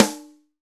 Index of /90_sSampleCDs/Roland L-CD701/SNR_Rim & Stick/SNR_Rim Modules
SNR RINGER01.wav